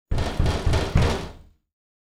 دانلود صدای در چوبی 2 از ساعد نیوز با لینک مستقیم و کیفیت بالا
جلوه های صوتی
برچسب: دانلود آهنگ های افکت صوتی اشیاء دانلود آلبوم صدای کوبیدن در چوبی از افکت صوتی اشیاء